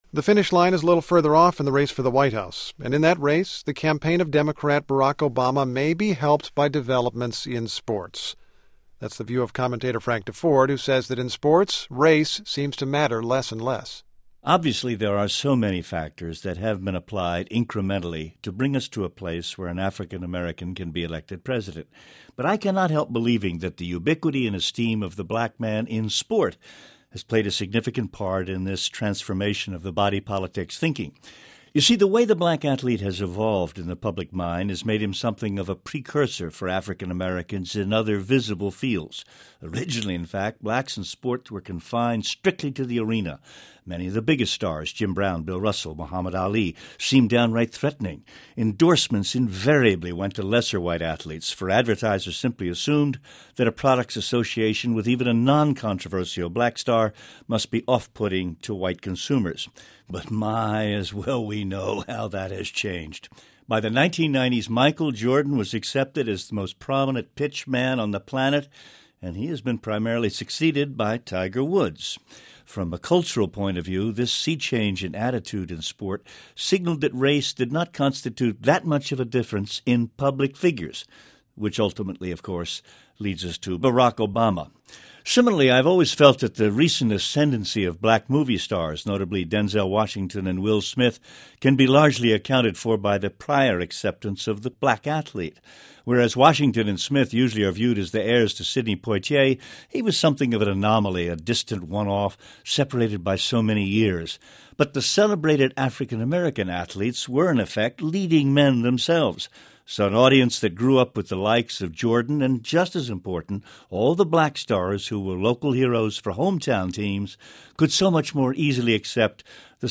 NPR Morning Edition's Frank Deford gives weekly commentary on a cross section of the world of sports.